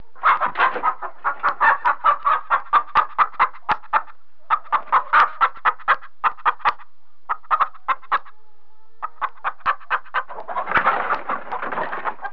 جلوه های صوتی
دانلود صدای حیوانات جنگلی 50 از ساعد نیوز با لینک مستقیم و کیفیت بالا